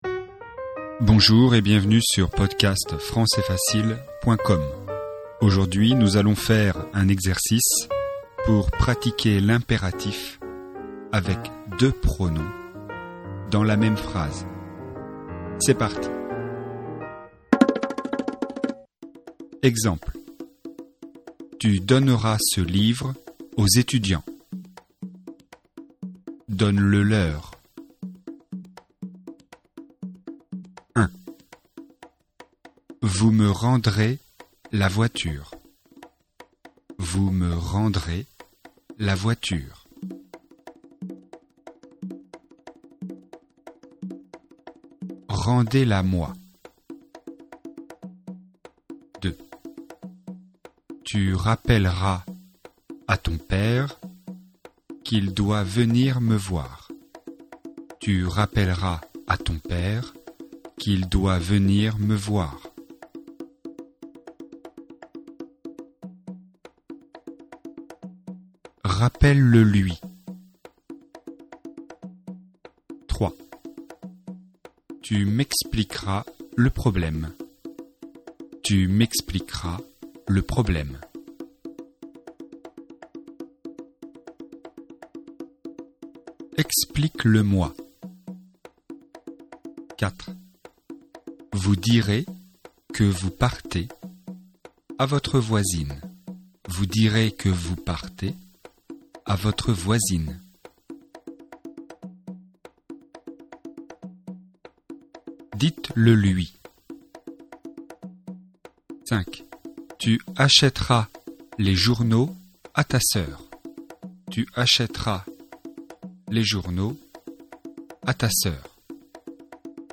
Exercice de grammaire, niveau intermédiaire (B1), sur le thème de l'impératif + 2 pronoms.